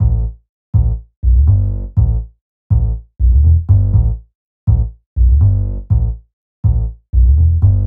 Bass 27.wav